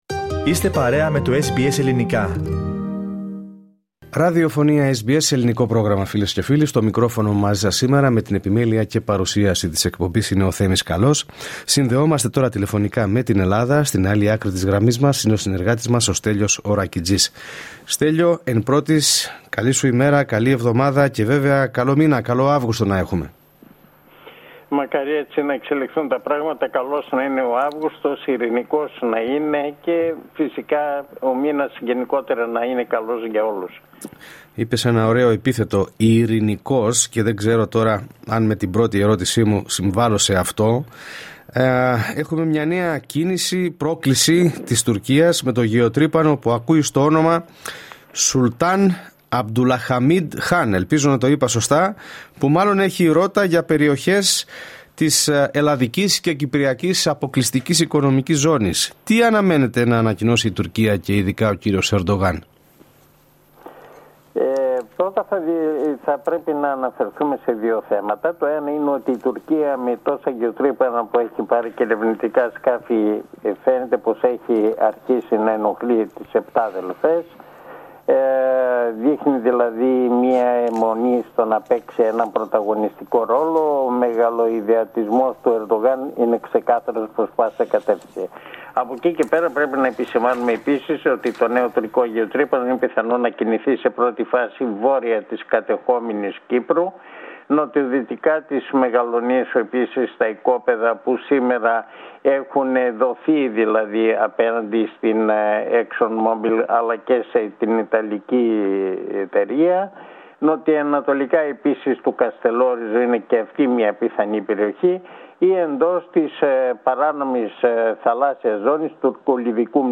Η εβδομαδιαία ανταπόκριση από την Ελλάδα.